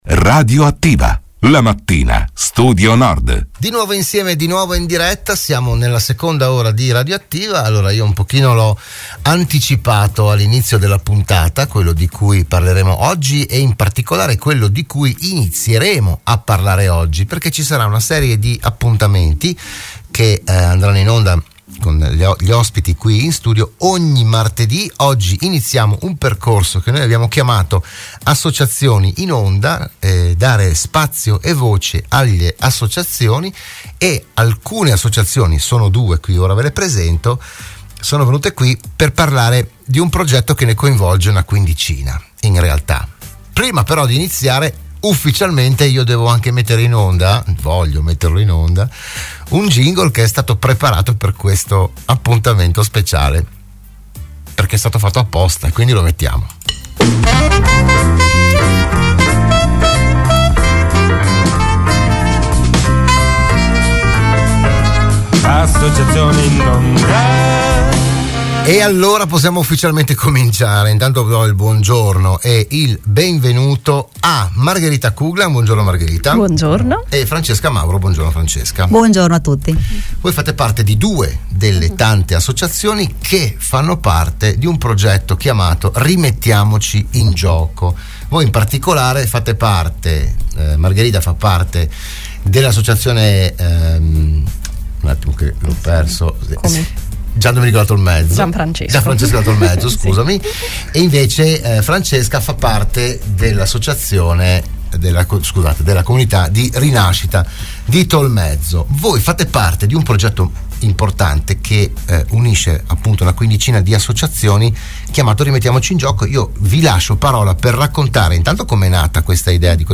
Dell’iniziativa si è parlato a Radio Studio Nord